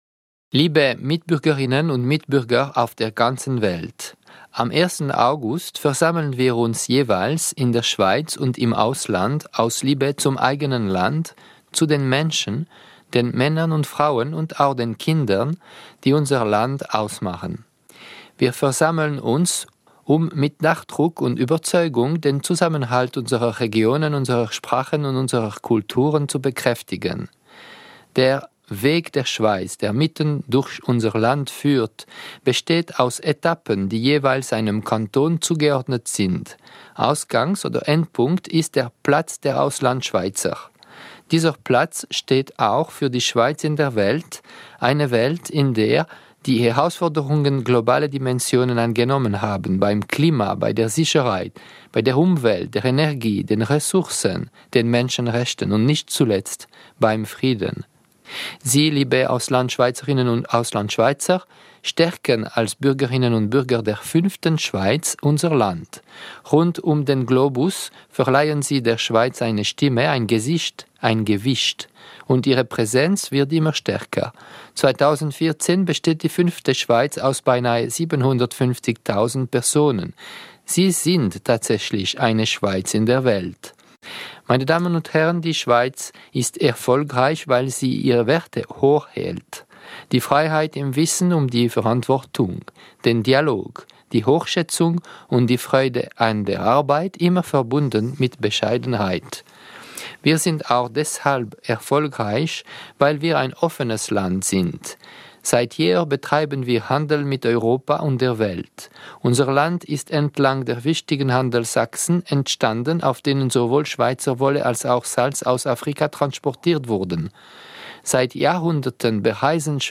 Ansprache von Bundespräsident Didier Burkhalter anlässlich der Bundesfeiern 2014 der Auslandschweizerinnen und Auslandschweizer.